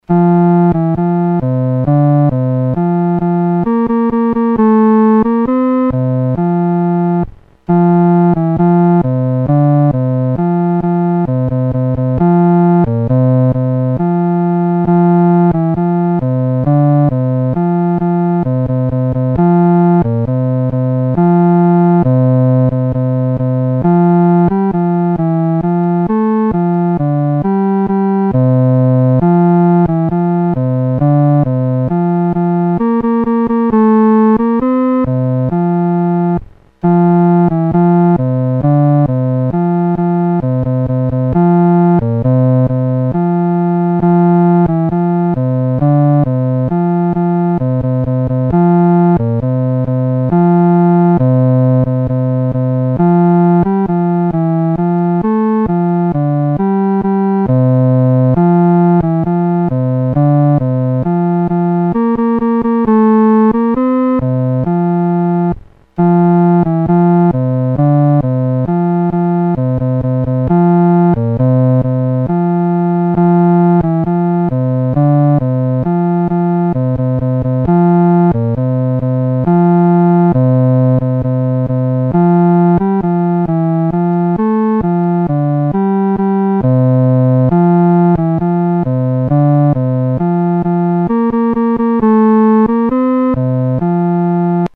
独奏（第四声）